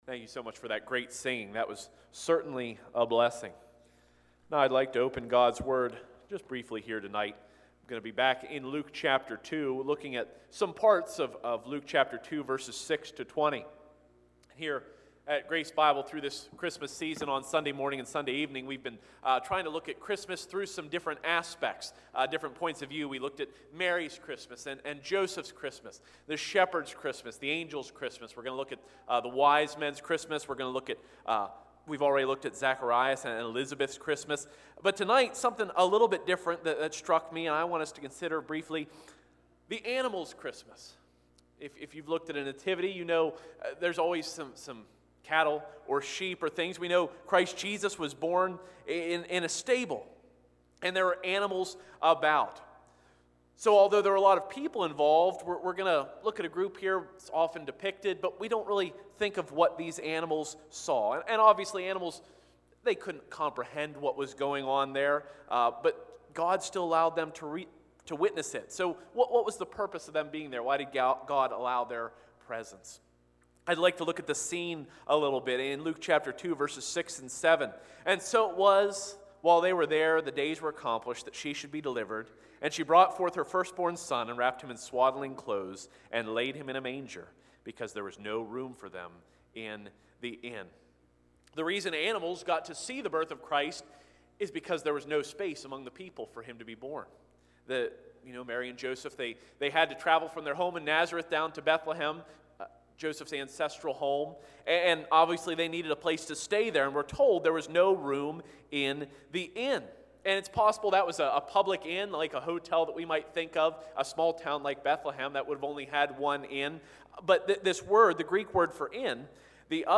Christmas Eve Service Luke 2:6-20